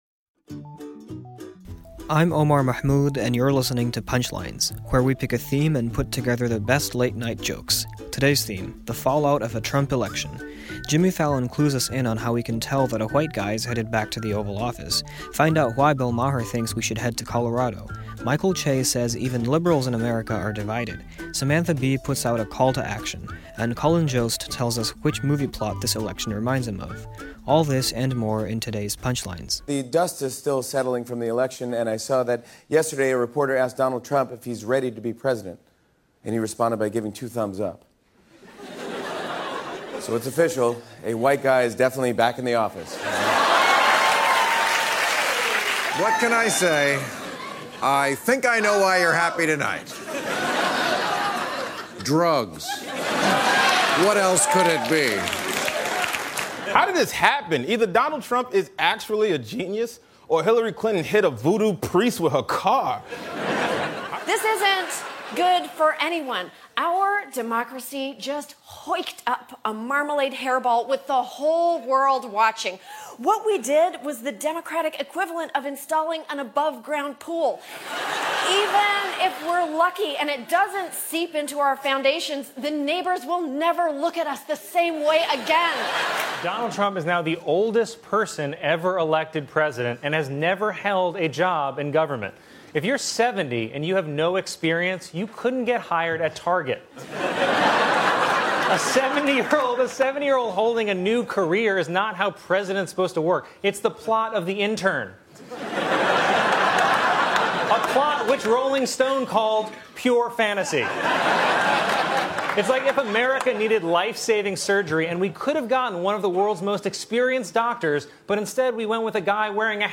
The late-night comics talk about the transition to Trump.